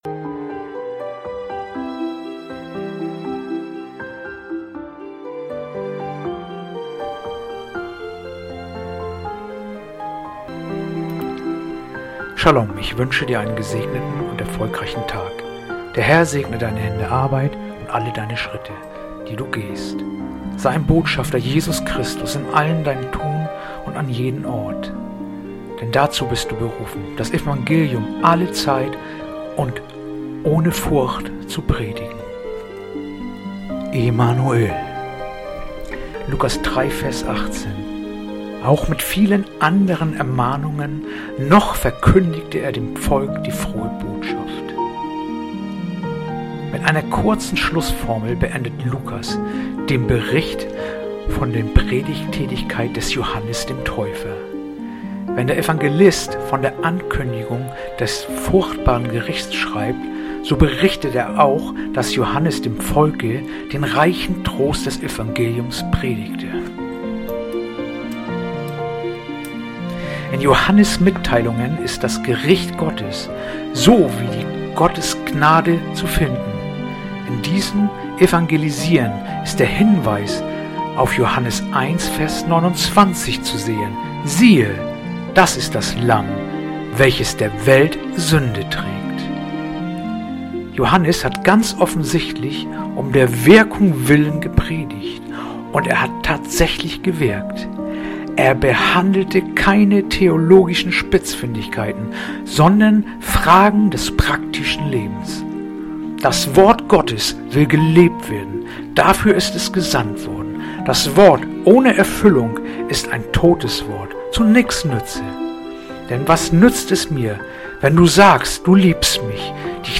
heutige akustische Andacht
Andacht-vom-17-Januar-Lukas3-18.mp3